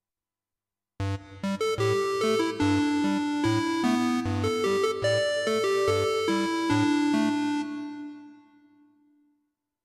19. I SUONI - GLI STRUMENTI XG - GRUPPO "SYNTH LEAD"
01. Square Lead 2
XG-10-01-SquareLead2.mp3